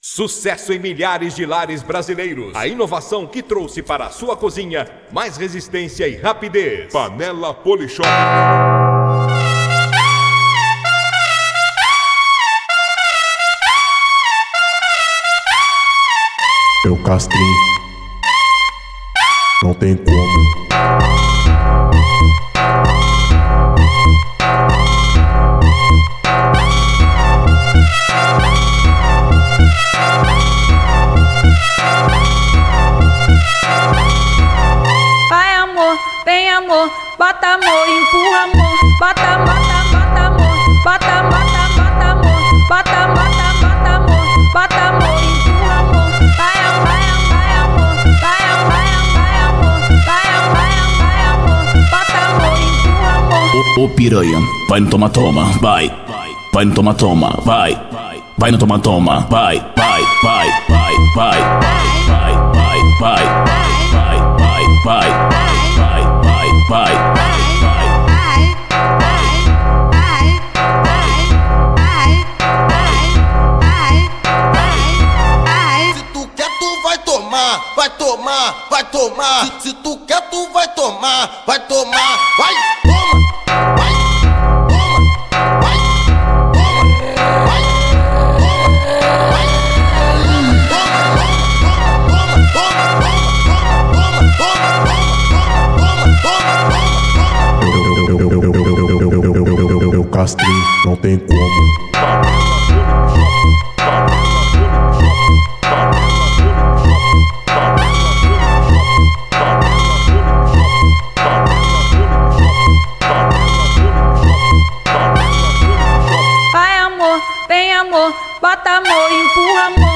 2024-06-30 14:59:43 Gênero: Phonk Views